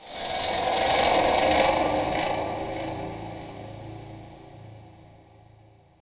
Index of /90_sSampleCDs/Roland L-CD701/GTR_GTR FX/GTR_E.Guitar FX
GTR CHUCK09R.wav